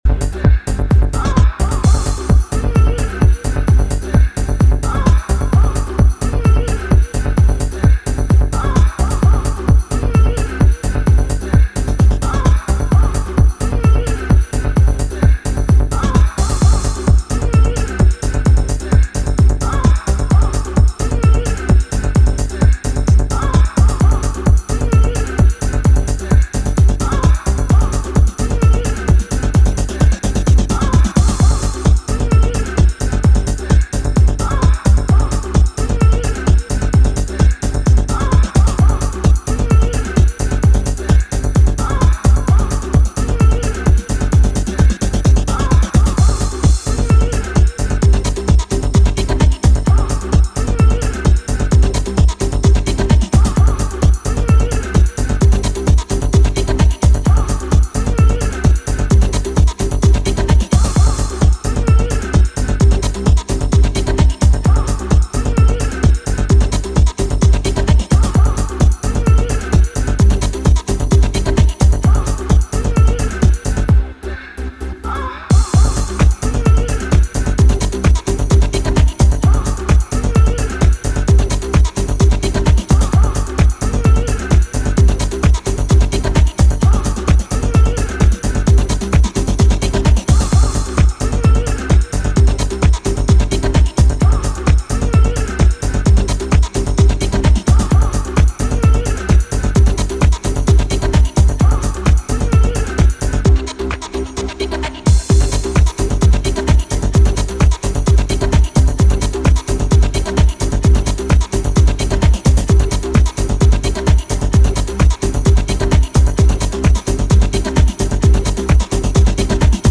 ユーフォリックなヴォイスサンプル連打とミニマルリフが鎮火しないパーティー状況に更なる燃料を投下する